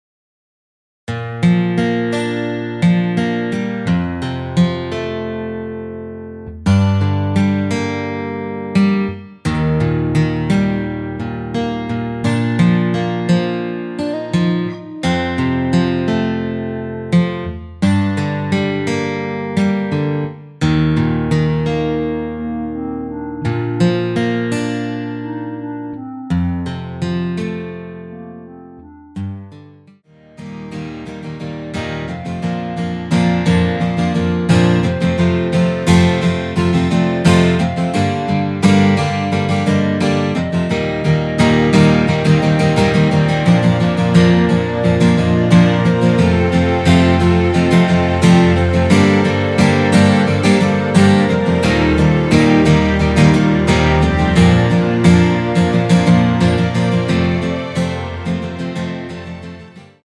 원키에서(-2)내린 멜로디 포함된 MR입니다.
앞부분30초, 뒷부분30초씩 편집해서 올려 드리고 있습니다.
중간에 음이 끈어지고 다시 나오는 이유는